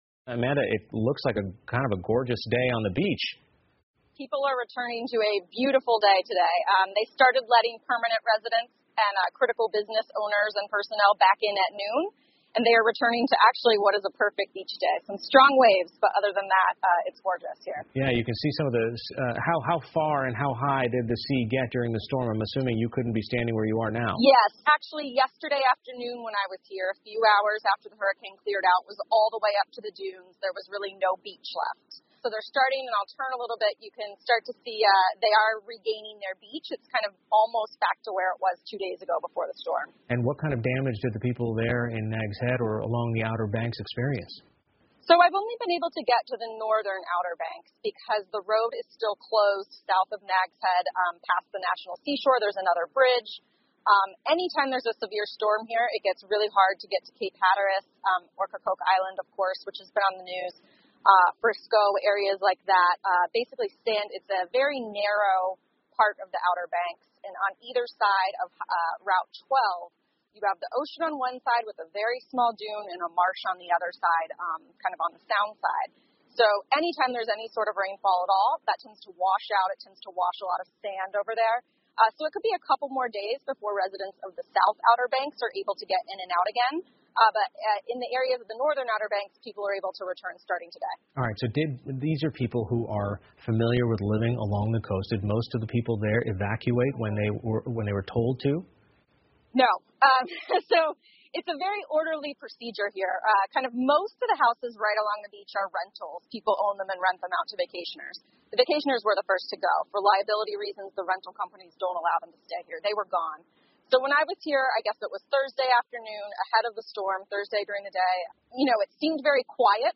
PBS高端访谈:飓风多利安过后的场景 听力文件下载—在线英语听力室